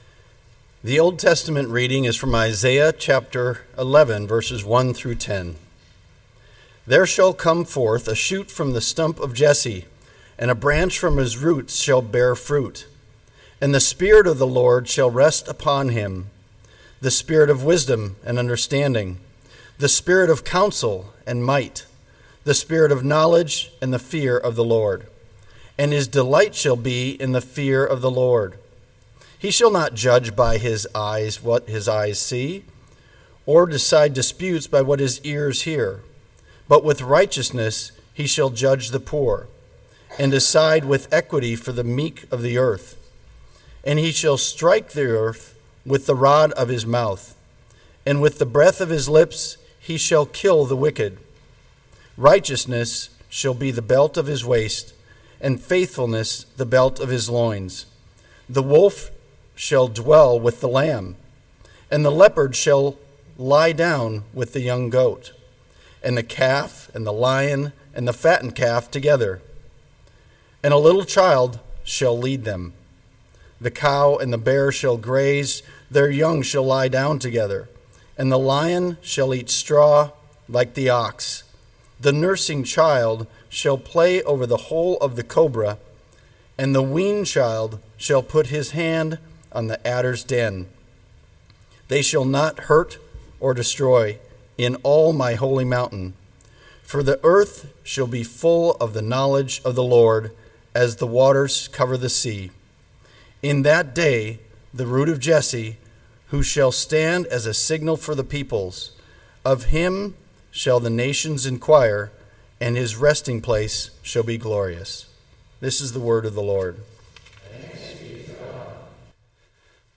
120725 SermonDownload Biblical Text: Isaiah 11:1-10 I was trying something different with this sermon.